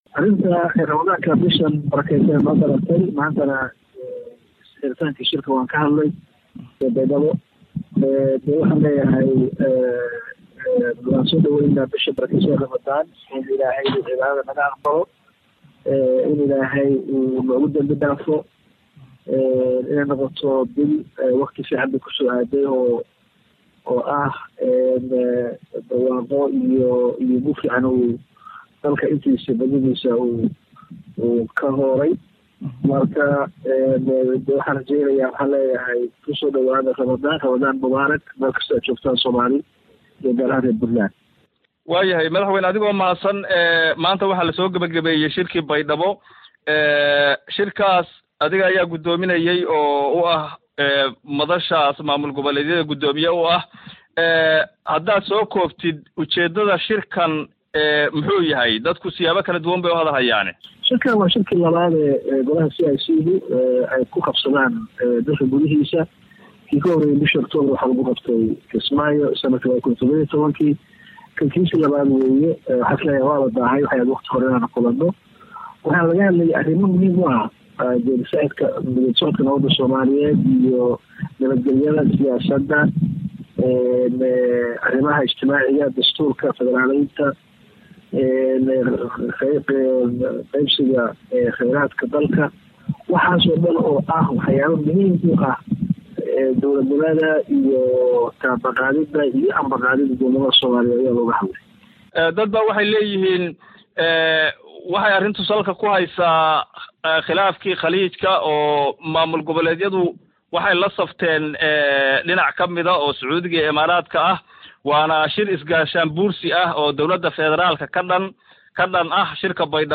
17 May 2018 (Puntlandes) Madaxweynaha Puntland Cabdiweli Maxamed Cali Gaas oo oo waraysi siiyay Radio Daljir ayaa ka hadlay shirkii Golaha Iskaashiga dowlad goboleedyada ee maanta lagu soo gabagabeyaay Baydhabo.